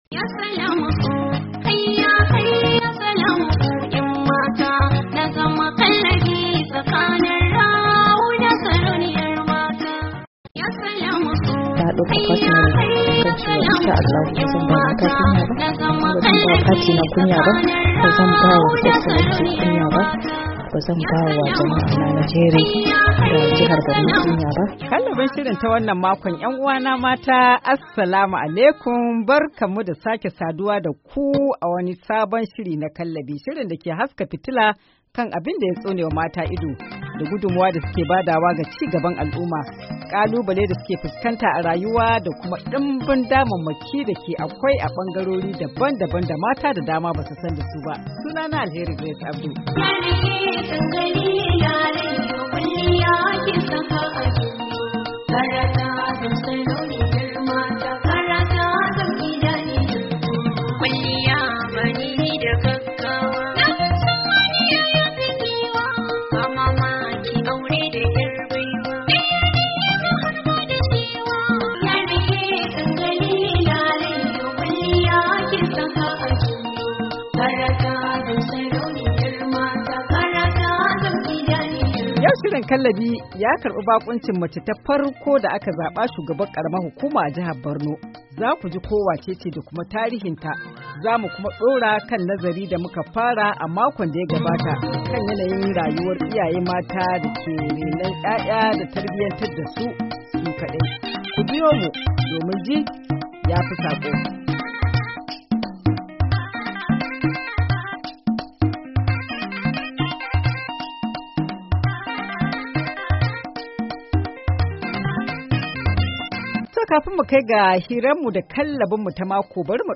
Shirin na wannan makon cigaban hira da Hajiya Inna Chiroma da ta yi tarihi a matsayin macen farko da aka zaba Shugabar karamar Hukuma a jihar Borno, shirin kallabi ya kuma haska fitila kan masababin barwa mata nawayar kula da ‘ya’yansu ko da kuwa suna tare da iyayen maza.